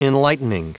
Prononciation du mot enlightening en anglais (fichier audio)